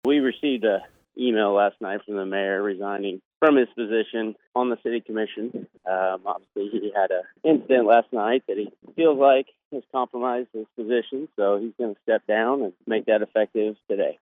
Interim City Manager Jason Hilgers provided the following statement to News Radio KMAN Thursday morning.